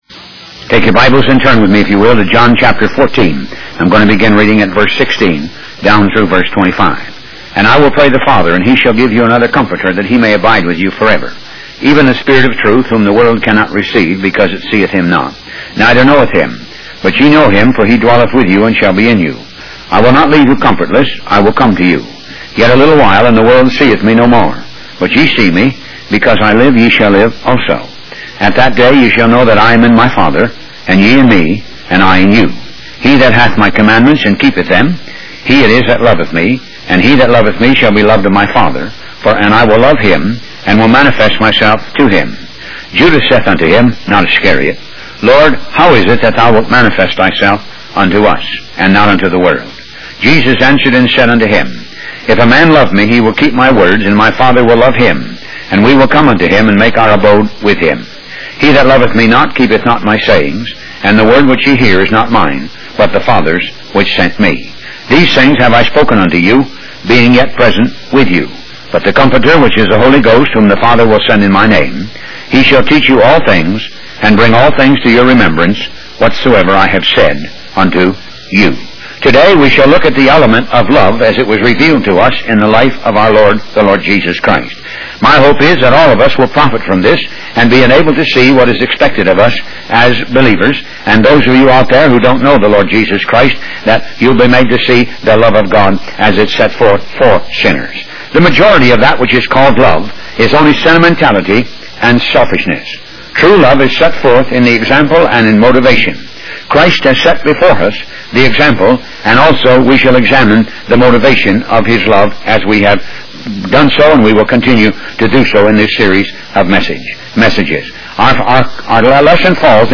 Talk Show Episode, Audio Podcast, Moga - Mercies of God Association and Love As Revealed in Christ on , show guests , about Love As Revealed in Christ, categorized as Health & Lifestyle,History,Love & Relationships,Philosophy,Psychology,Christianity,Inspirational,Motivational,Society and Culture